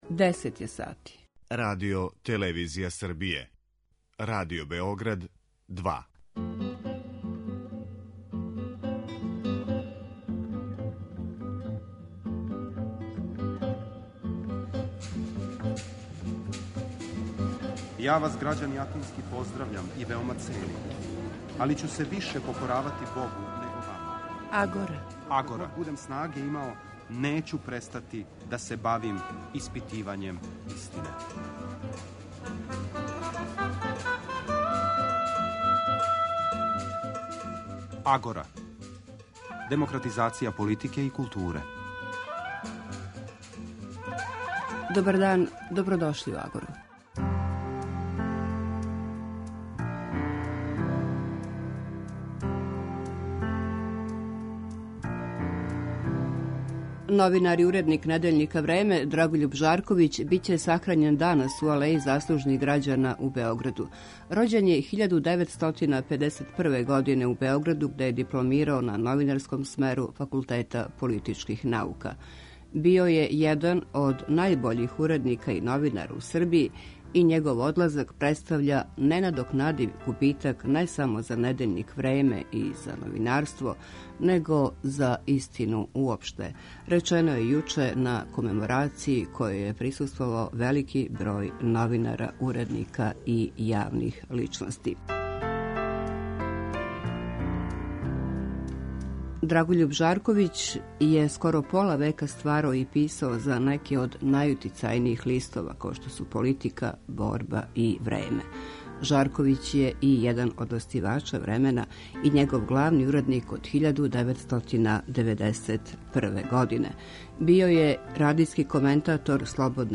Радио-магазин који анализира феномене из области политичког живота, филозофије, политике и политичке теорије.